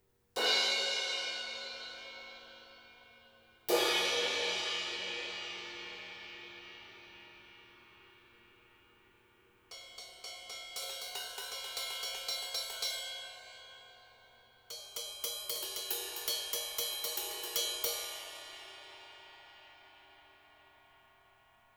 To show the results of the mod, I recorded samples using both the modded 205 and a stock version of the same mic.
Note, though, that I haven’t applied any post-processing to the sounds.
Cymbals (crash, then ride)
I find the differences obvious in the cymbal decay, where the stock mic exhibits some high frequency distortion.
Stock mic – Cymbals [3.8Mb]
Apex-205-Stock-Cymbals.wav